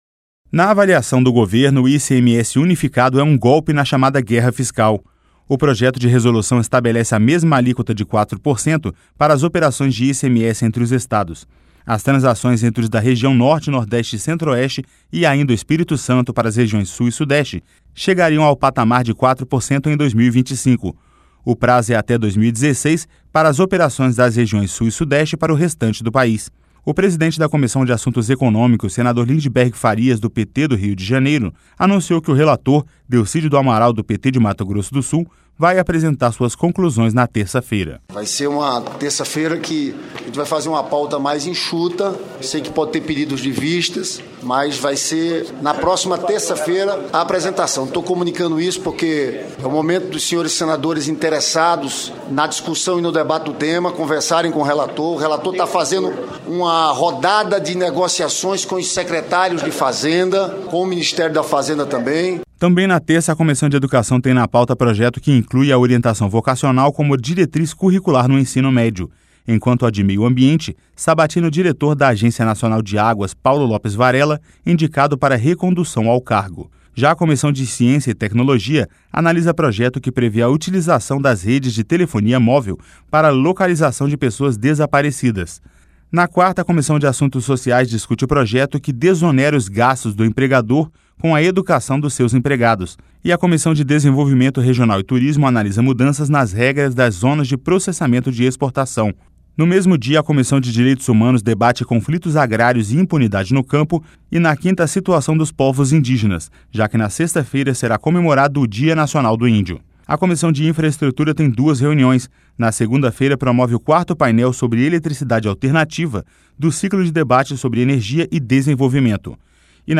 LOC: A COMISSÃO DE ASSUNTOS ECONÔMICOS PODE VOTAR NESTA SEMANA A UNIFICAÇÃO DO ICMS NAS OPERAÇÕES INTERESTADUAIS.